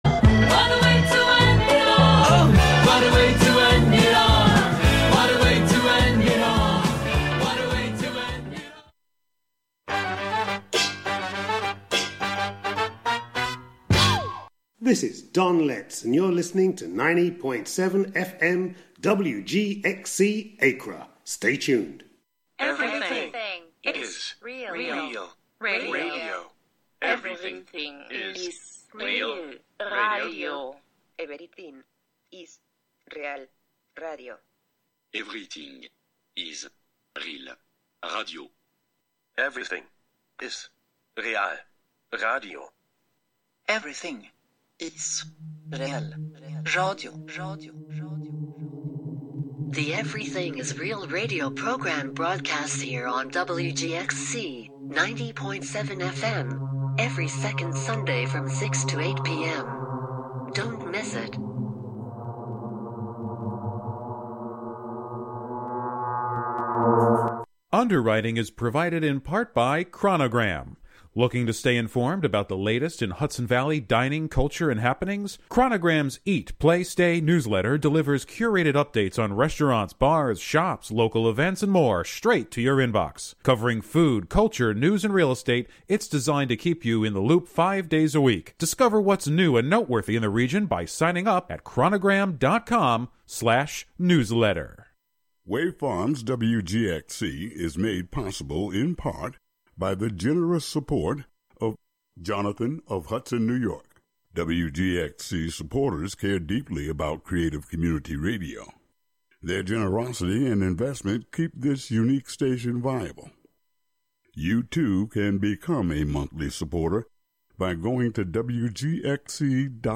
Tune in for radio art, radio theatre, experiments, live shows, and more.